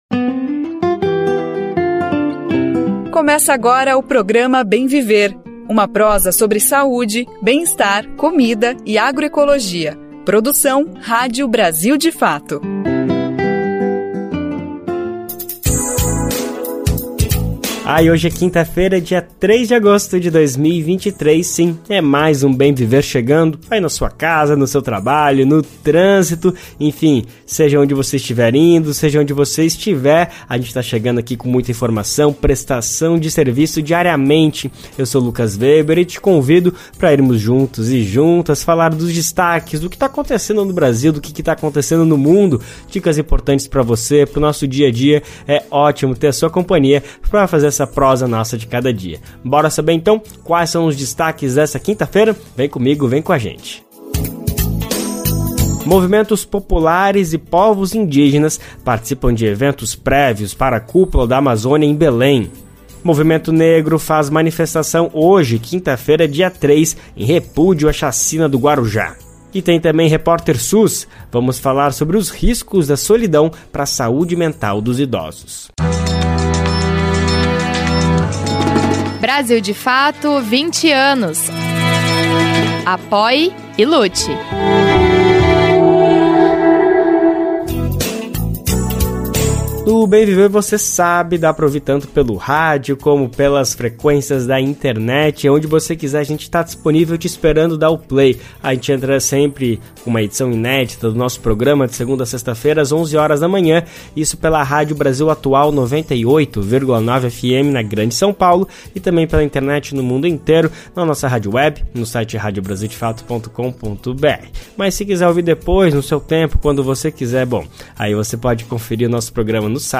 O programa de rádio Bem Viver desta quinta-feira (3) apresenta os dados de duas pesquisas que apontam a região de moradia, a cor da pele ou a condição socioeconômica, entre outros fatores, como determinantes para qualidade de vida ou garantia de direitos.